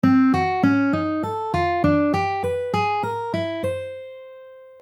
Sounds kind of wrong?
12-Ton-Line.mp3